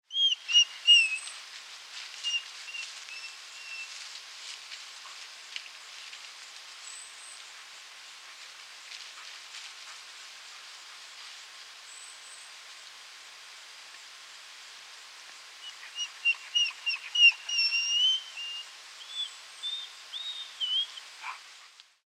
Fiepen:
1565_Wolf_Fiepen_short.mp3